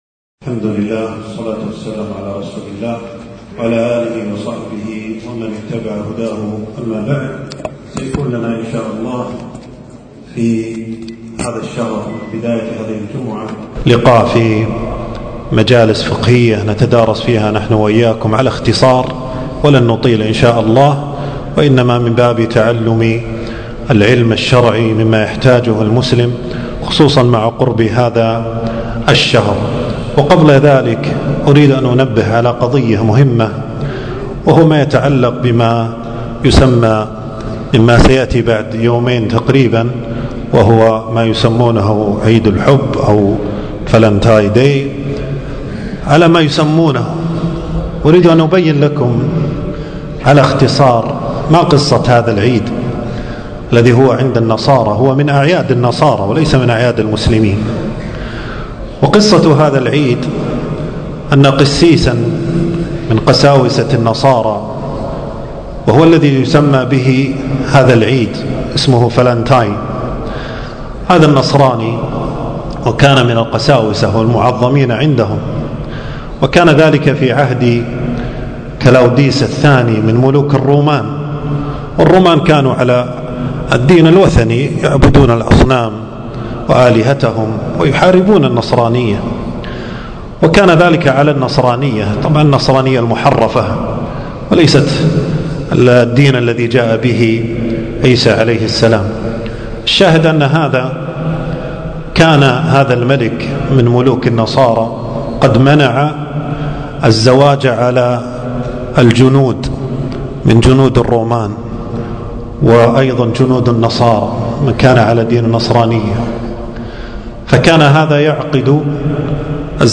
تنزيل تنزيل التفريغ محاضرة بعنوان: المجالس الفقهية الرمضانية - المجلس الأول.
في مسجد أبي سلمة بن عبدالرحمن بن عوف.